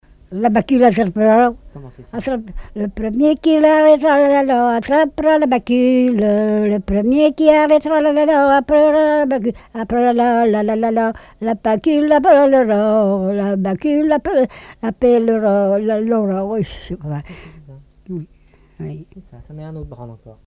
Couplets à danser
collecte en Vendée
Répertoire de chants brefs et traditionnels
Pièce musicale inédite